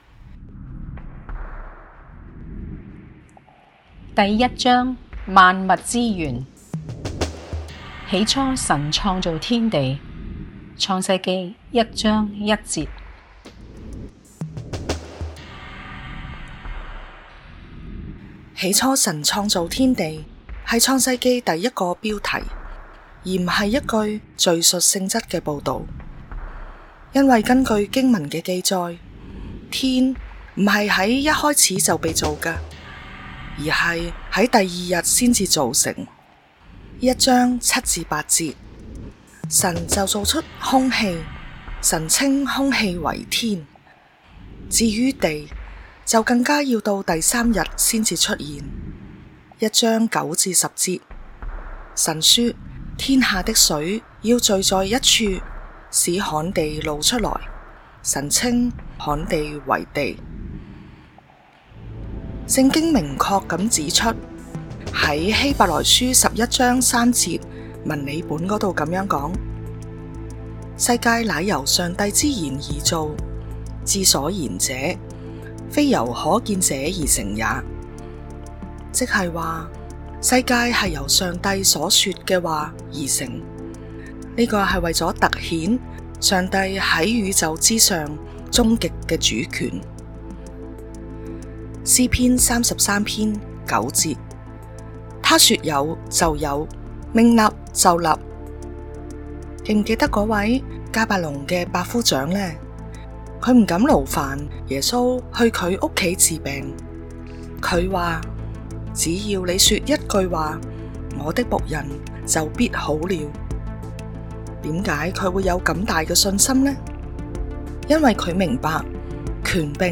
（本章【選擇題】答案在下一章） 補充資料及經文出處： 「神就造出空氣……神稱空氣為天」（一7～8） 有聲版為講說流暢，省略了以下顏色部分： 因為根據經文的記載，「天」不是在一開始就被造的，而是在第二日才造成：「神就造出空氣……神稱空氣為天」（一7～8）。